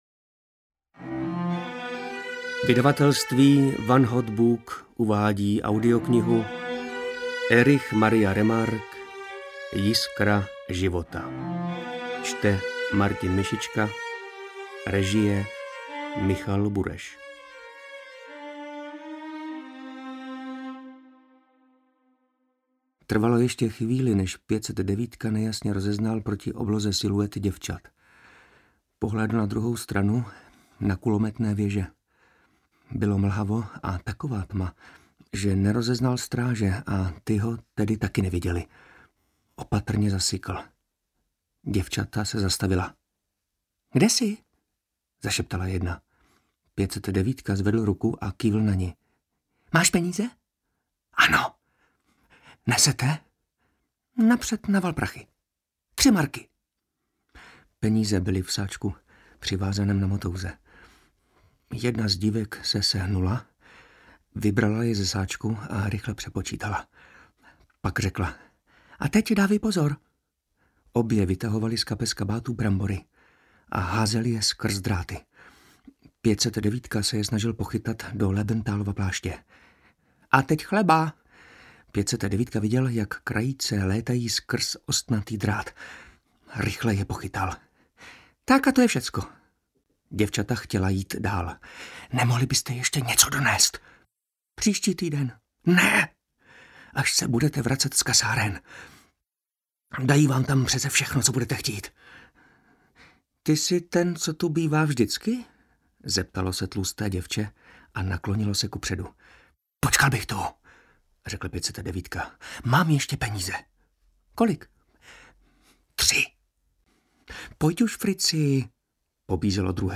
Jiskra života audiokniha
Ukázka z knihy